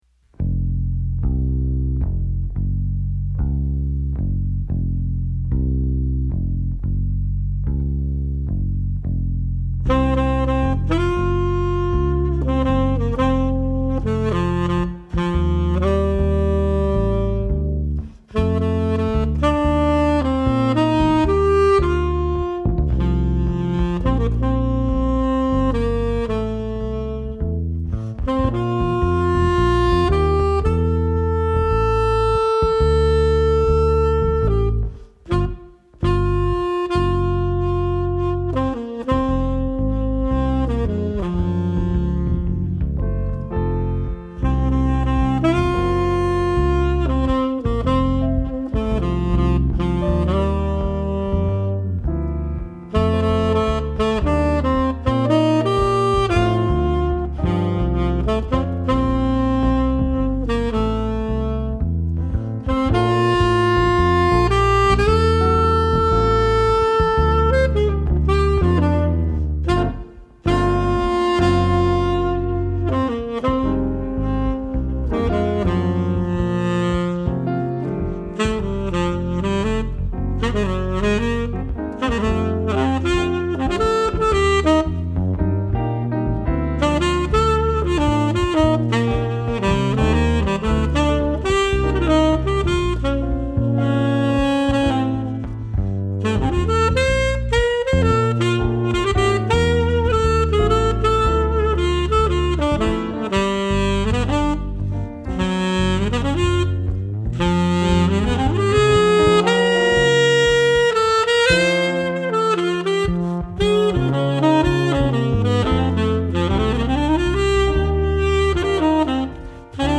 perform jazz favorites with a contemporary spin
saxes, flutes, other toys
guitar
drums
bass
Genre: Jazz